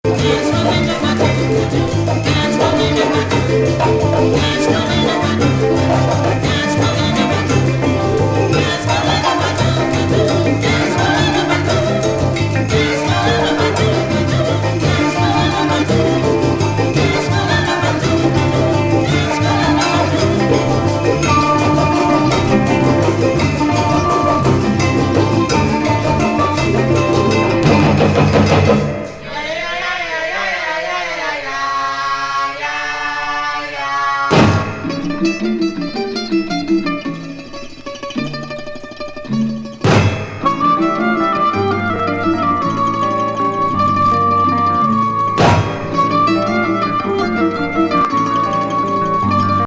黒魔術ブードゥー・サイケ・